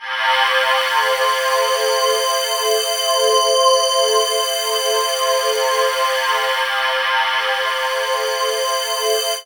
36ag01pad-cM.wav